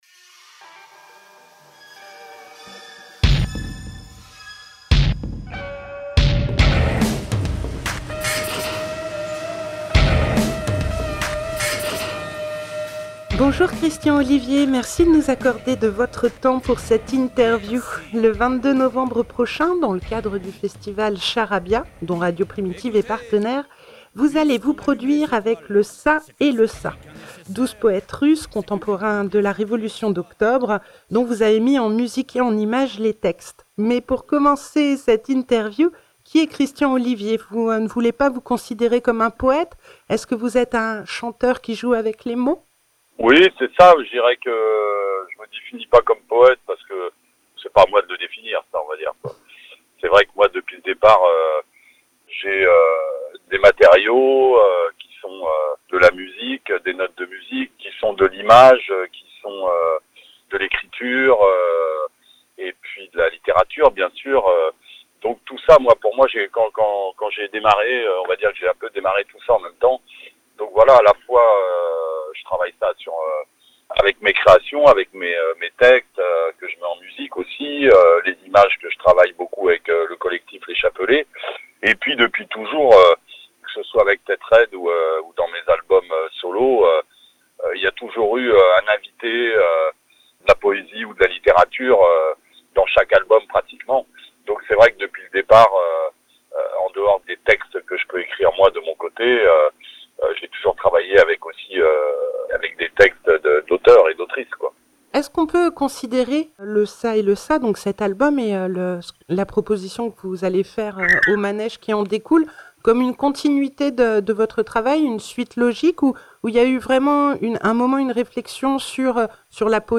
Interview à l'occasion du Charabia Festival